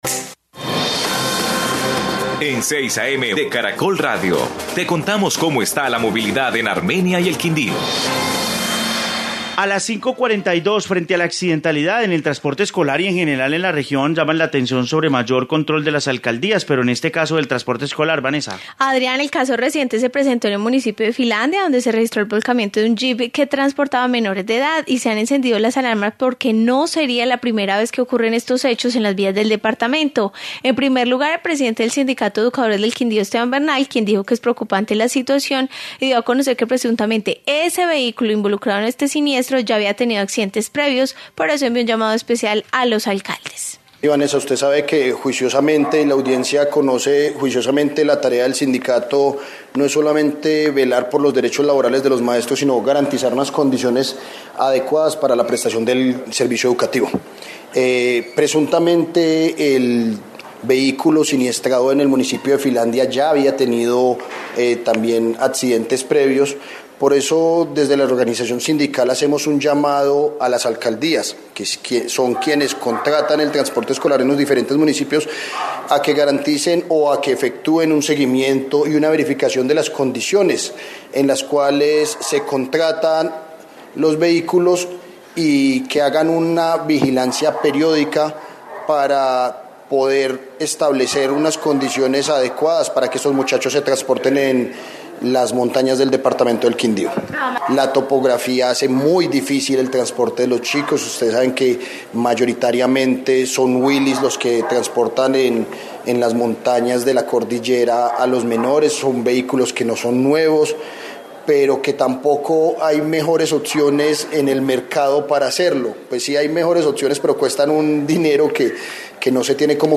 Informe sobre accidentalidad